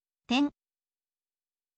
ten